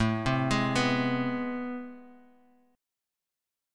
AmM9 Chord
Listen to AmM9 strummed